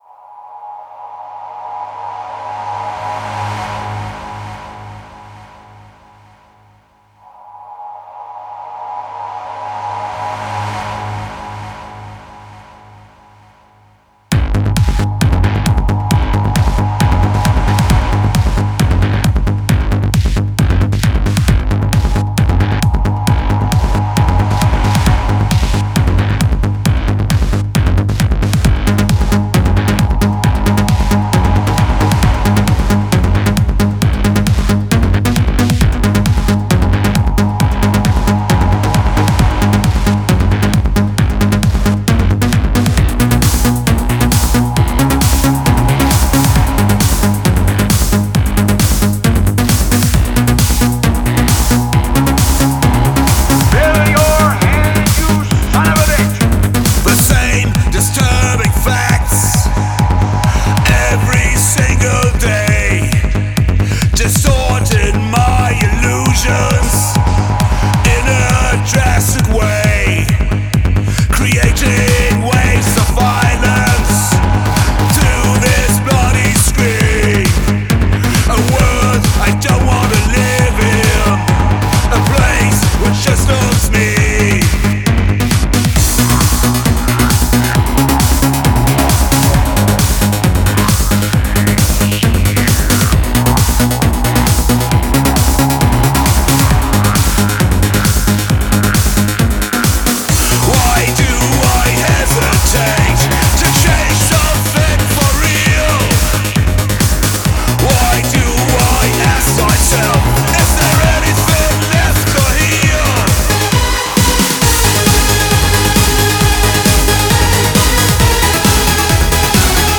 Real Synthetic Audio EBM and Industrial net radio show blog and RSS feed.